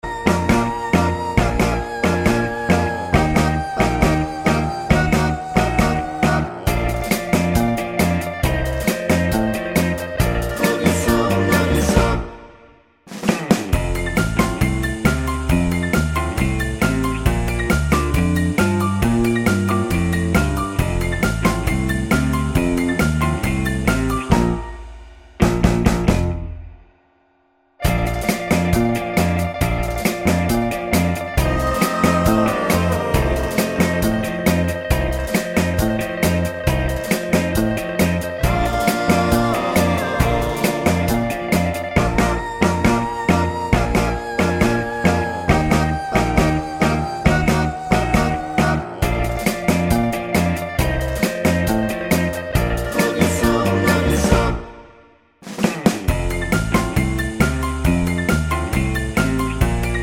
no Backing Vocals Pop (1960s) 2:43 Buy £1.50